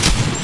fireenemy.ogg